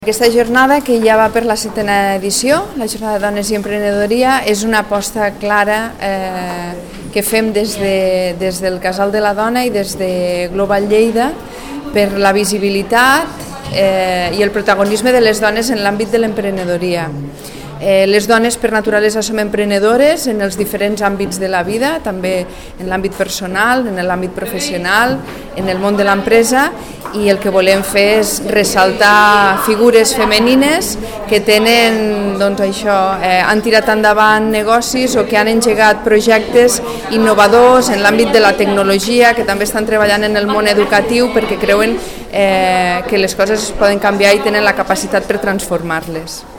tall-de-veu-de-la-regidora-sandra-castro-sobre-la-jornada-dones-i-emprenedoria